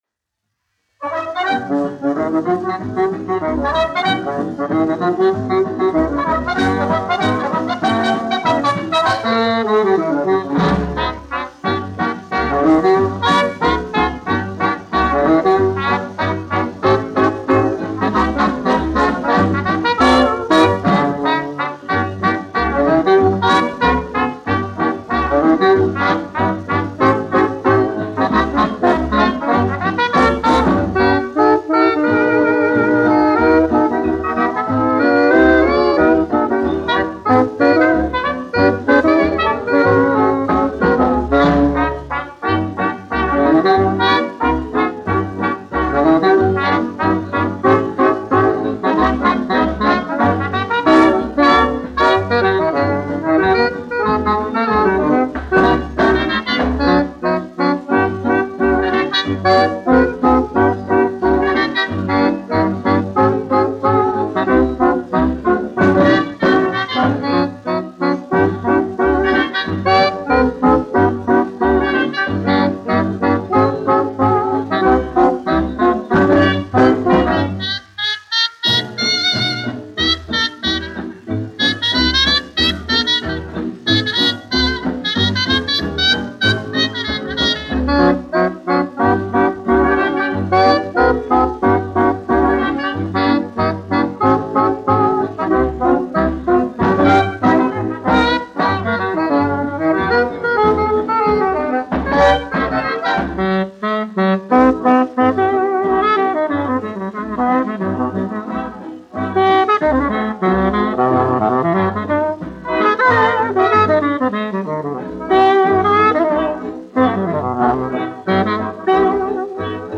1 skpl. : analogs, 78 apgr/min, mono ; 25 cm
Fokstroti
Populārā instrumentālā mūzika
Skaņuplate
Latvijas vēsturiskie šellaka skaņuplašu ieraksti (Kolekcija)